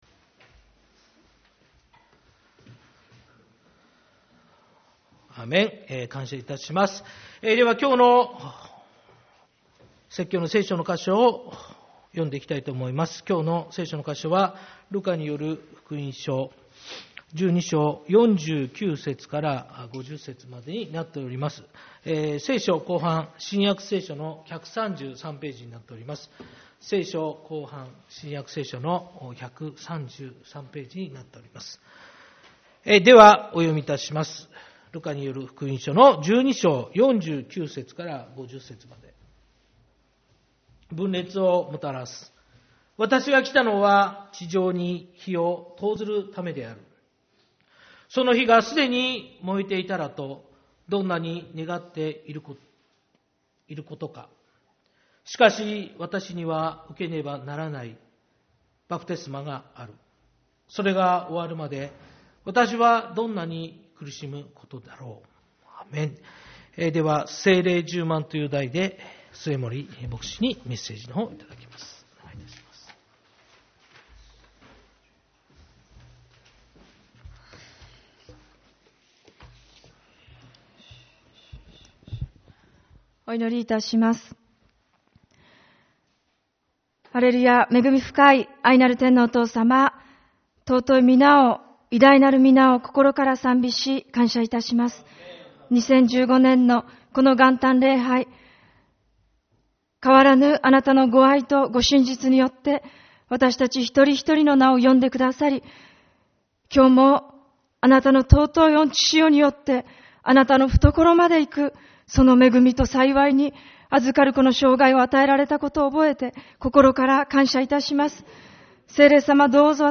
元旦礼拝 「聖霊充満」 ルカによる福音書12.49-50